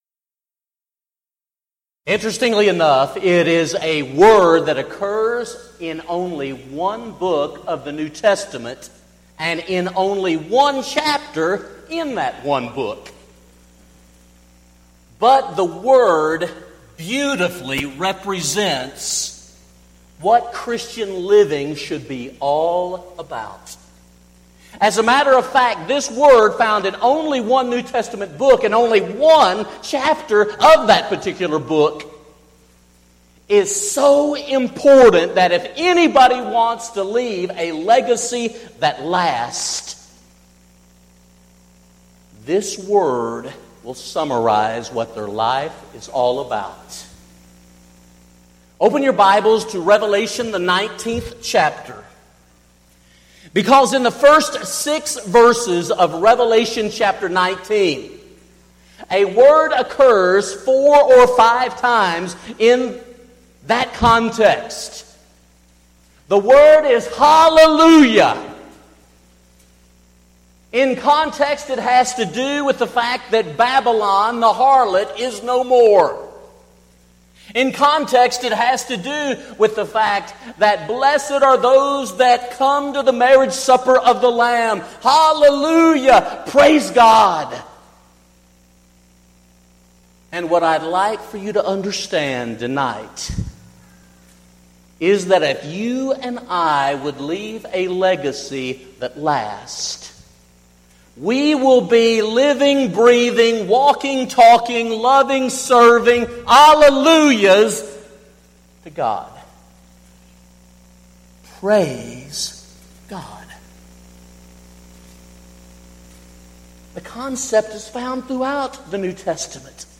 Event: 4th Annual Arise Workshop Theme/Title: Biblical Principles for Congregational Growth
lecture